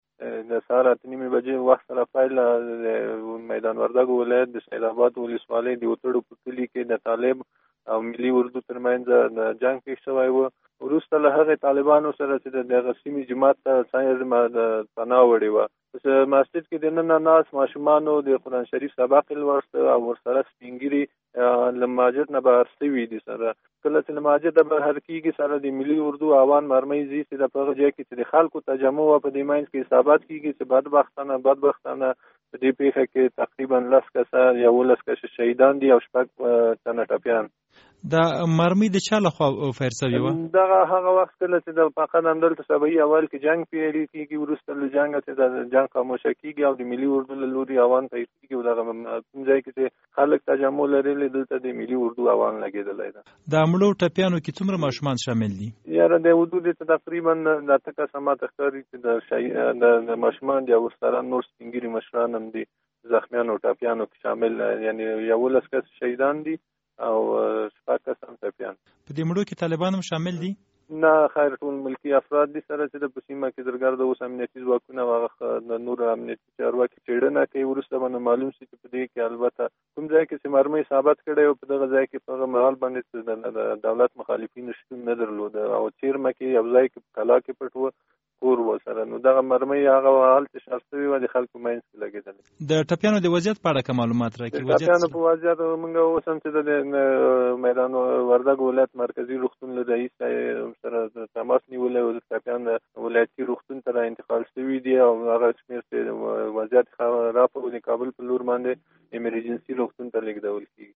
د میدان وردګو د ولایتي شورا له منشي شریف الله هوتک سره مرکه: